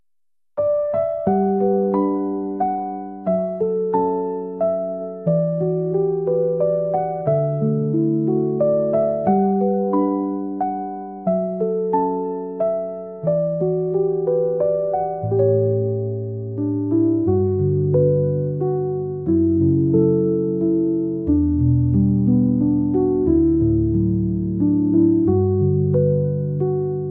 Peaceful moments in a park sound effects free download